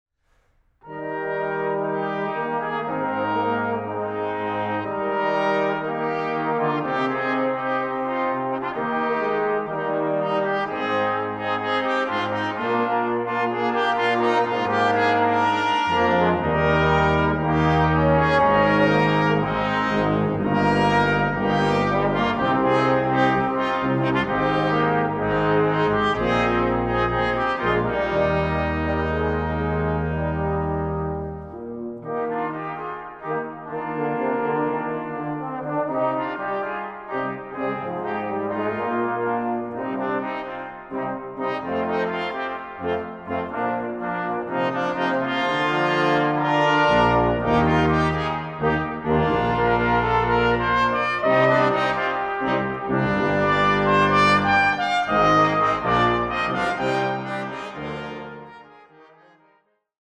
Aufnahme: Jesus-Christus-Kirche Berlin-Dahlem, 2011